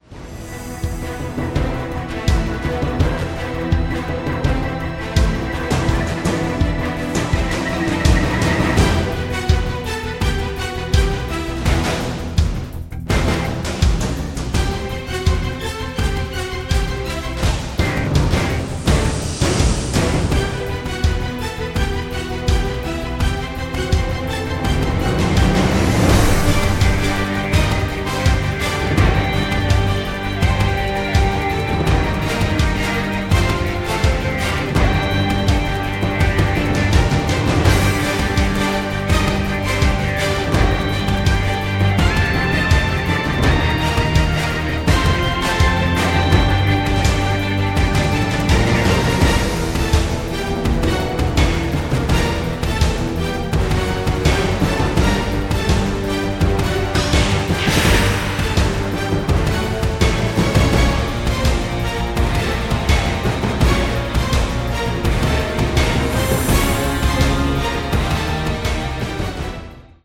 SCORE PRESENTATION